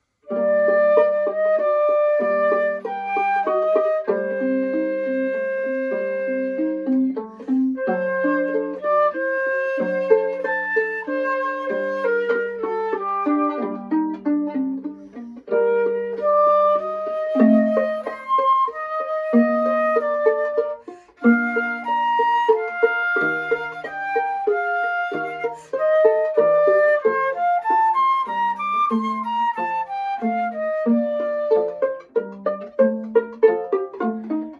Adaptation pour flûte & 2 violons